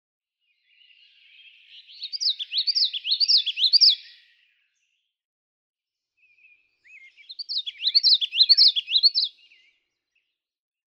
June 3, 2016. Sax-Zim Bog, Minnesota.
♫213—one song from each of two neighboring males
213_Common_Yellowthroat.mp3